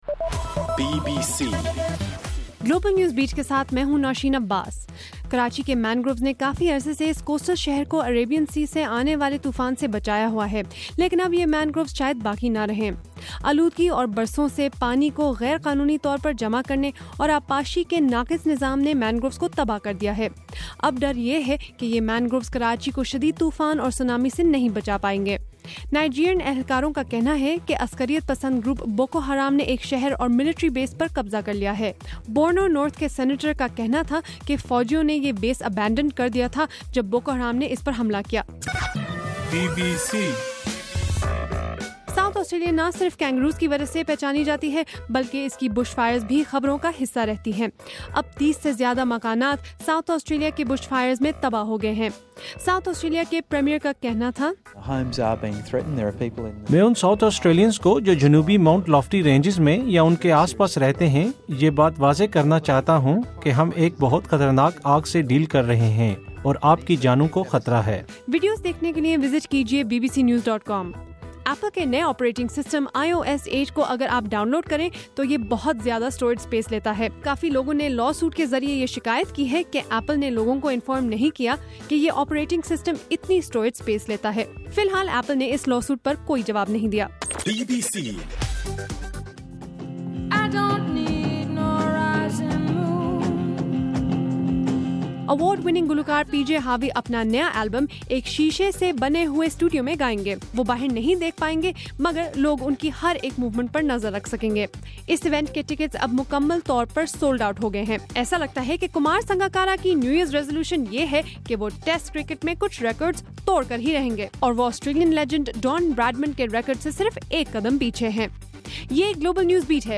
جنوری 4: رات 9 بجے کا گلوبل نیوز بیٹ بُلیٹن